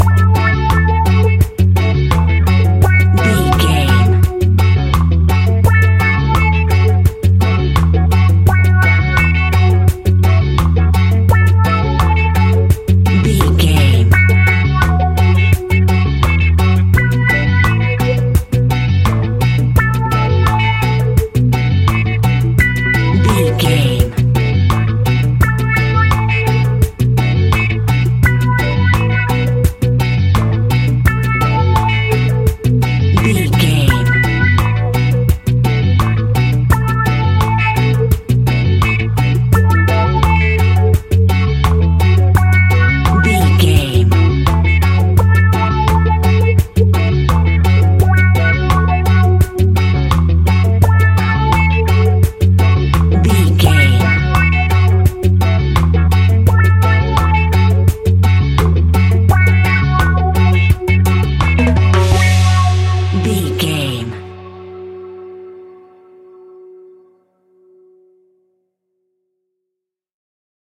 Classic reggae music with that skank bounce reggae feeling.
Aeolian/Minor
F#
reggae instrumentals
laid back
chilled
off beat
drums
skank guitar
hammond organ
percussion
horns